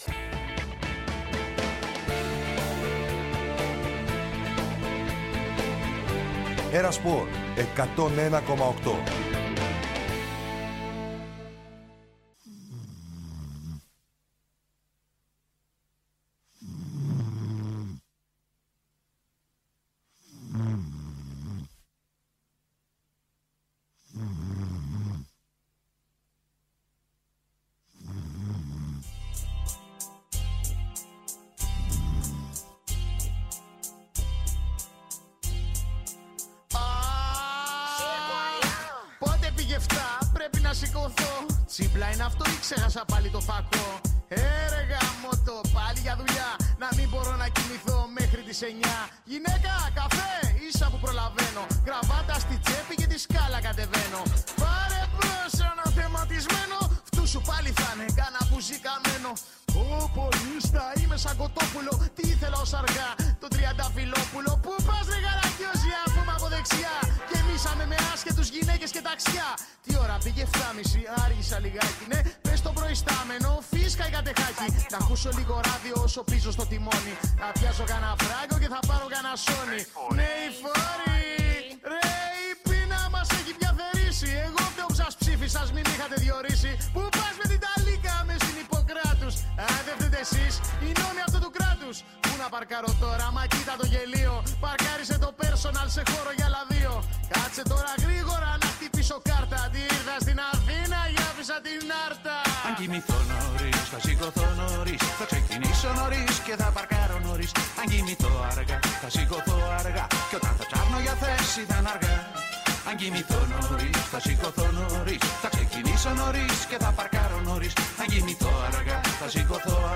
Συνεντεύξεις και ρεπορτάζ για όσα συμβαίνουν εντός και εκτός γηπέδων.